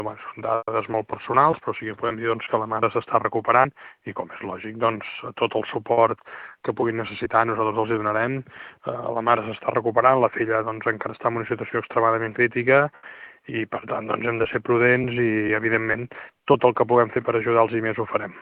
L’alcalde ha reiterat el suport institucional a les víctimes i ha assegurat que el municipi i els serveis públics estaran al seu costat en tot allò que necessitin.